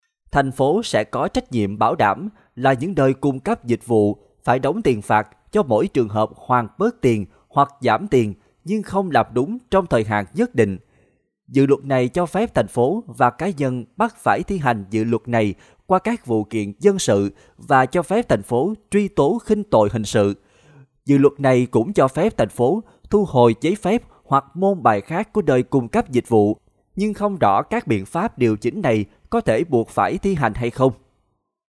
• 10Vietnamese Male No.5
Vigor and vitality